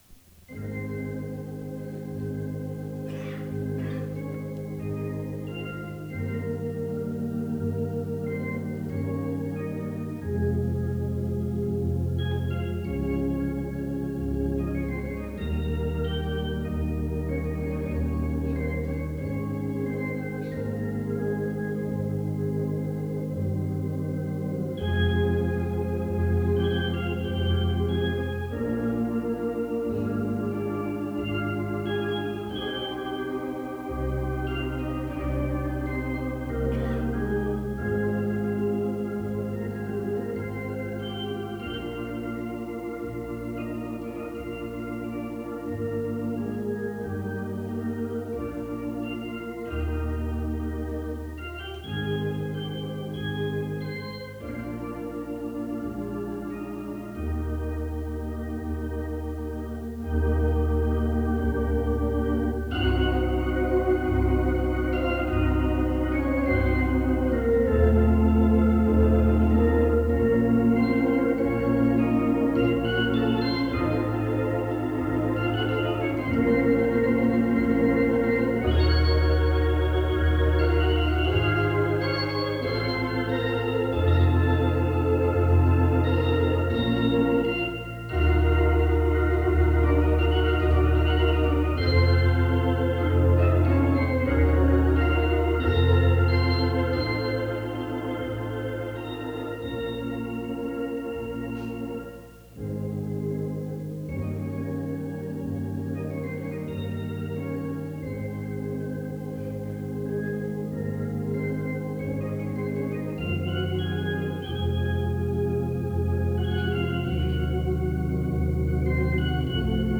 Concierto de órgano
en el Hotel Meliá Don Pepe / Marbella 1972
Escuchar framentos del concierto interpretado en un órgano Hammond T-222.